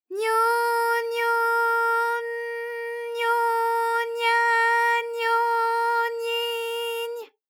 ALYS-DB-001-JPN - First Japanese UTAU vocal library of ALYS.
nyo_nyo_n_nyo_nya_nyo_nyi_ny.wav